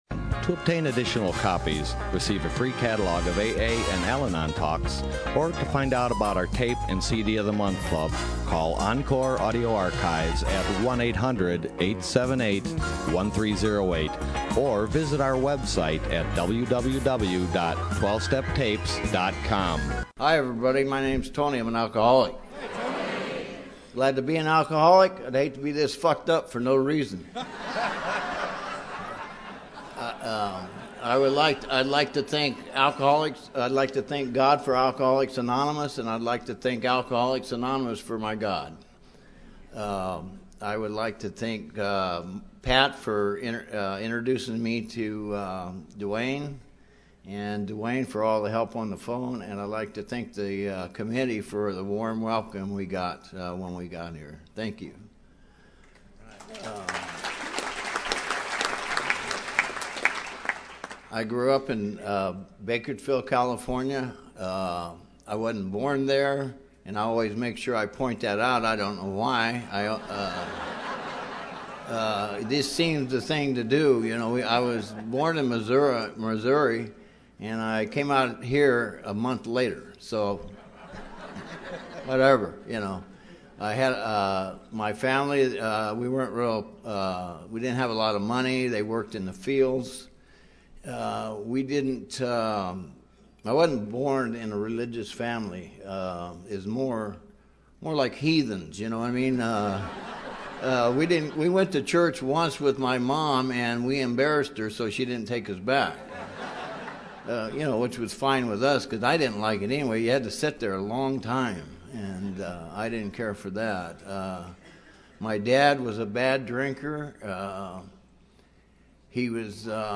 Orange County AA Convention 2012